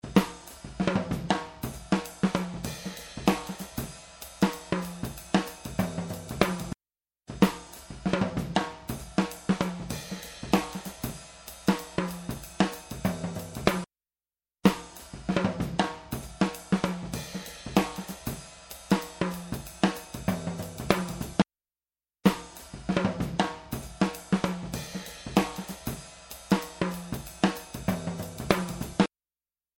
In each of the following listening tests you will hear four playbacks of a musical performance clip. Three of the clips were recorded with vintage Neve 1073 modules and one of the clips was recorded with a Vintech X73 preamp. In each case one mic was passively split to all four preamps so that each preamp could amplify the same exact performance utilizing the same exact microphone. The only variable is the preamps.
Drums
drum test.mp3